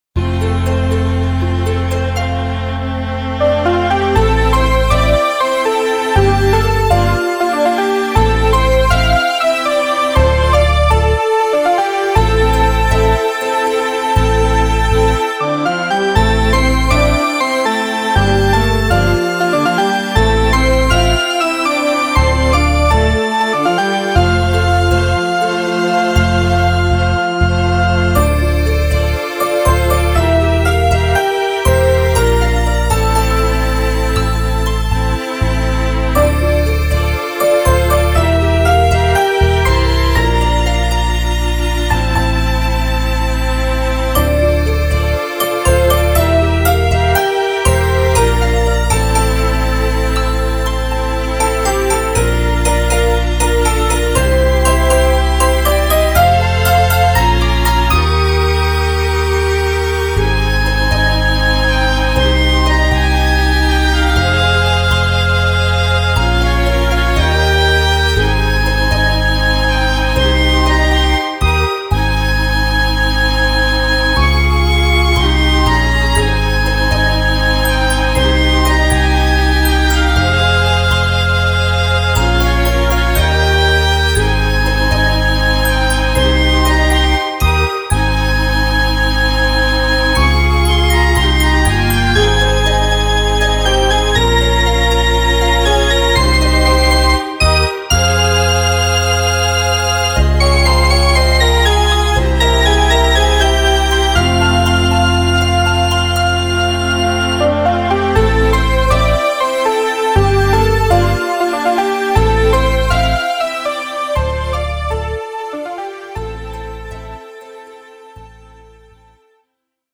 フリーBGM 街・拠点・村など のんびり・ほのぼの